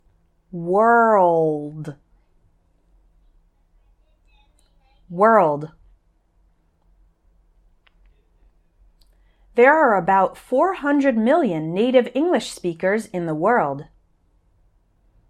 I’ll say each one once slowly, once at normal speed, and then I’ll say an example sentence – so listen and repeat after me each time.